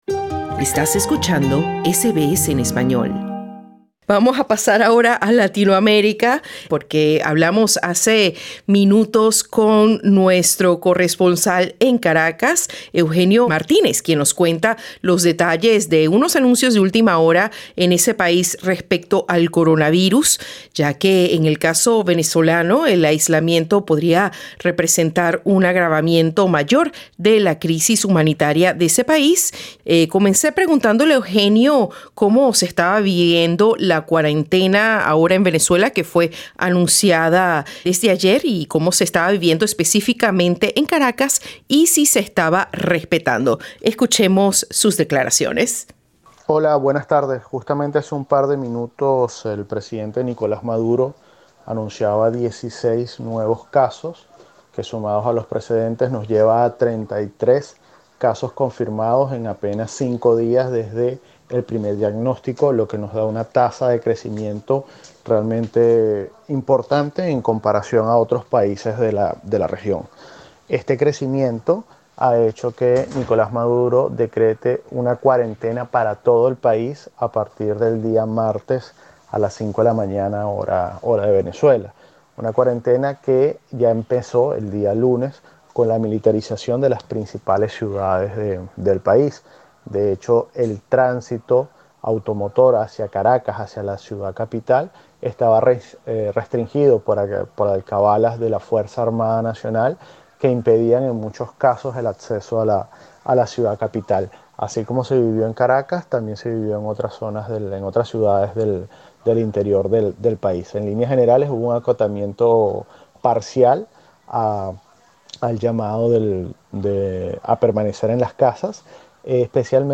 La llegada del coronavirus a Venezuela hace temer un desastre sanitario en un país cuyos servicios públicos esenciales están en estado crítico. Escucha nuestro informe desde Caracas.